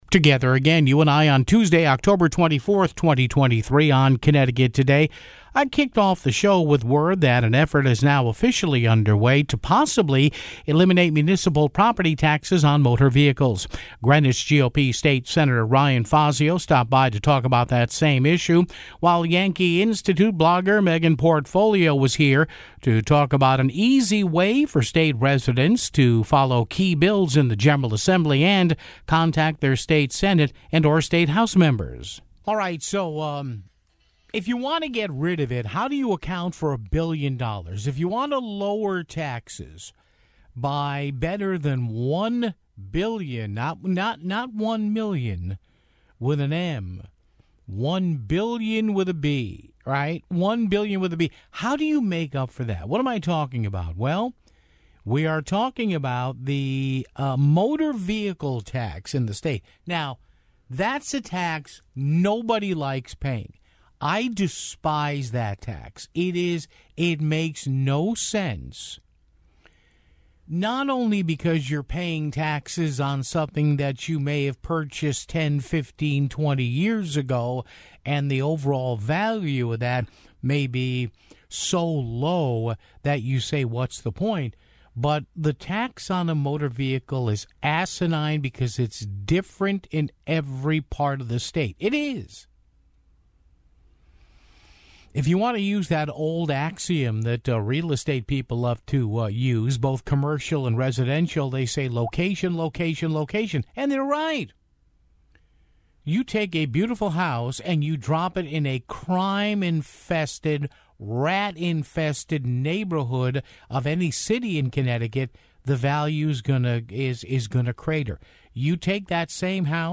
Greenwich GOP State Sen. Ryan Fazio stopped by to talk about the same issue (12:39)